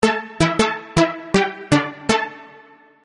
合成器循环
Tag: 80 bpm Hip Hop Loops Synth Loops 518.32 KB wav Key : Unknown